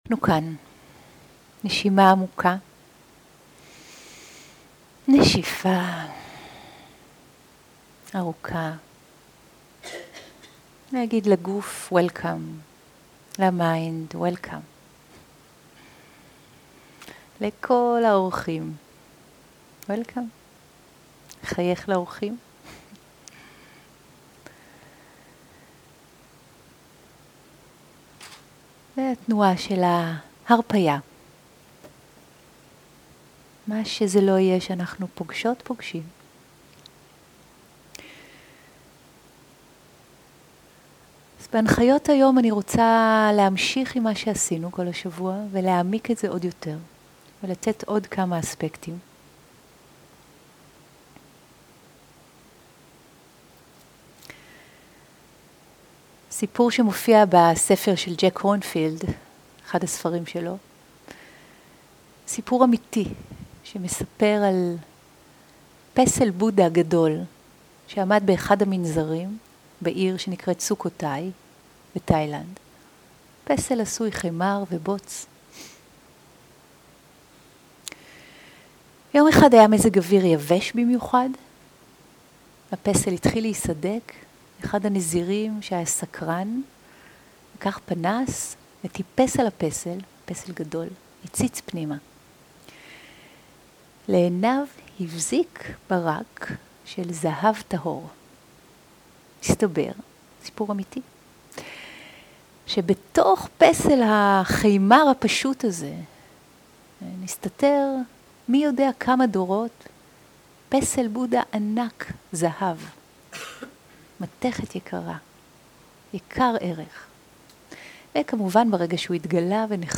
סוג ההקלטה: שיחת הנחיות למדיטציה
עברית איכות ההקלטה: איכות גבוהה מידע נוסף אודות ההקלטה